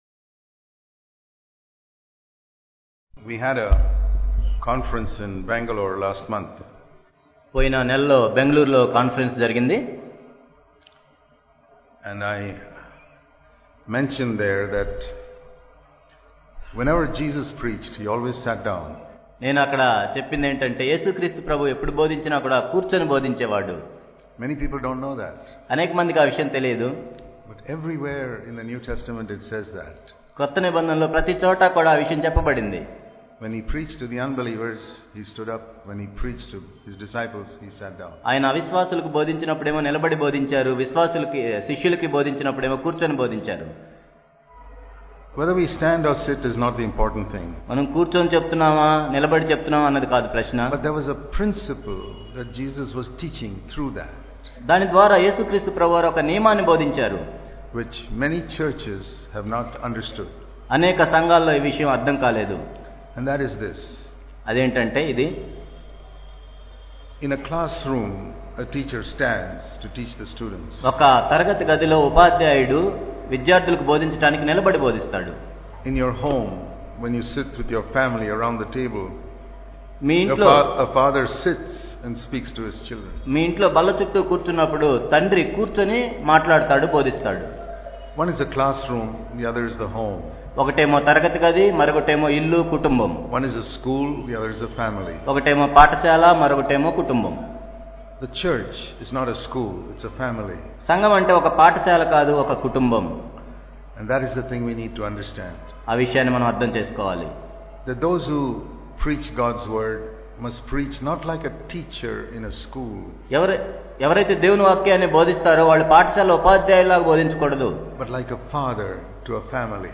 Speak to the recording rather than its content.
Gaining The Glory of Christ An Overcoming Life and True Fellowship Watch the Live Stream of the Hyderabad Conference 2015. Theme: An Overcoming Life and True Fellowship. 28th & 29th November 2015.